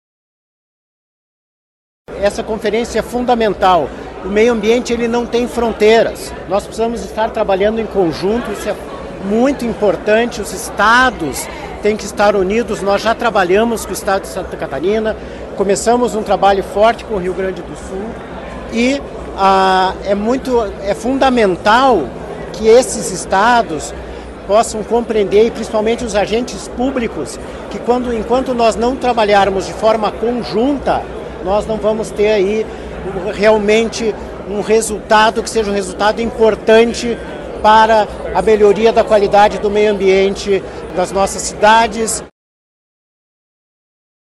Na cerimônia de abertura, realizada pela manhã no Teatro Guaíra, o Paraná apresentou iniciativas voltadas à sustentabilidade.